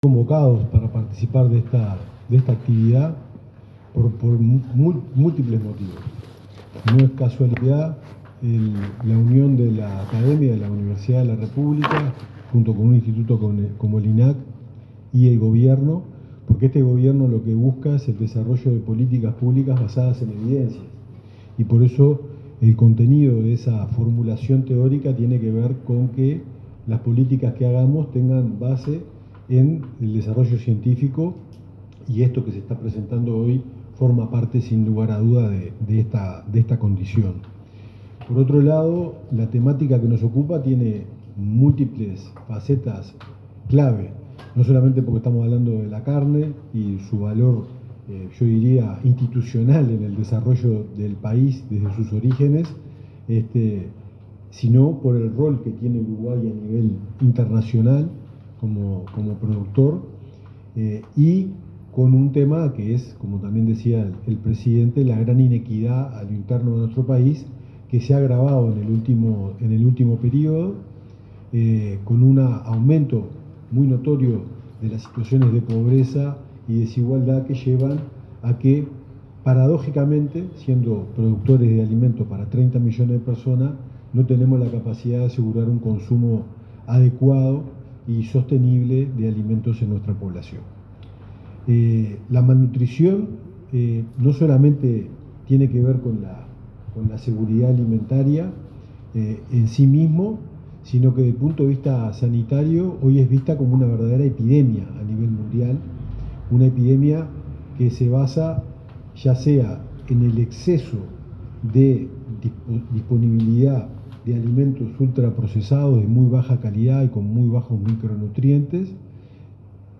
Palabras del subsecretario de Salud Pública, Leonel Briozzo
Palabras del subsecretario de Salud Pública, Leonel Briozzo 27/11/2025 Compartir Facebook X Copiar enlace WhatsApp LinkedIn En la presentación de los valores nutricionales de carne bovina, que contiene datos elaborados por el Instituto Nacional de Carnes junto con el Ministerio de Salud Pública, INIA y Udelar, se expresó el subsecretario de Salud, Leonel Briozzo.